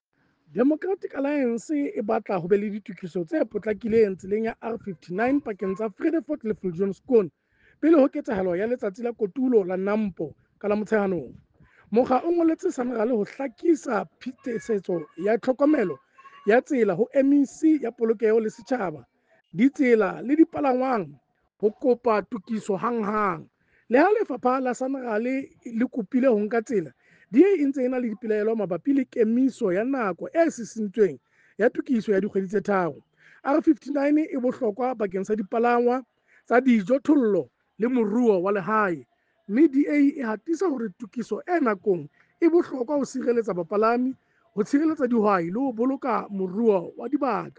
Sesotho soundbite by Cllr Kabelo Moreeng